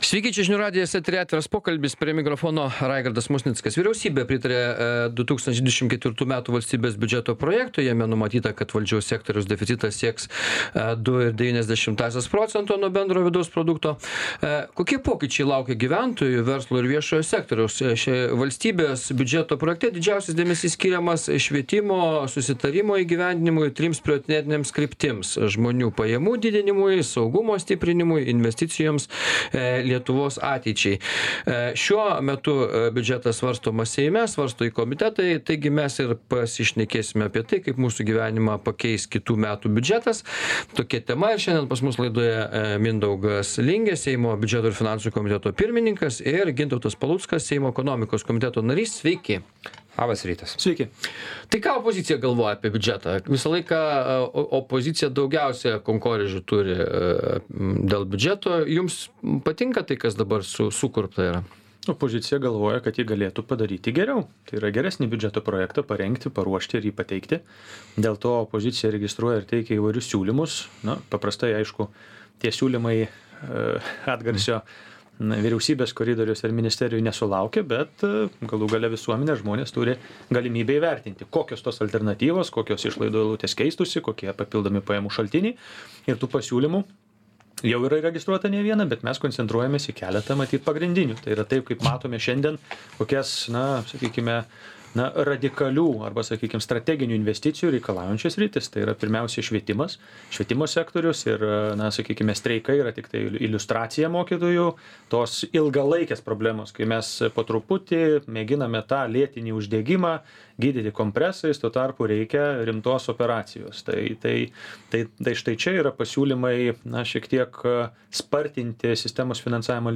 Diskutuoja Seimo Biudžeto ir finansų komiteto pirmininkas Mindaugas Lingė ir Seimo Ekonomikos komiteto narys Gintautas Paluckas.